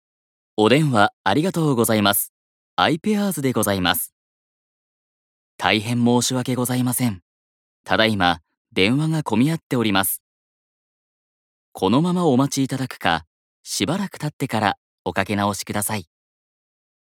通常版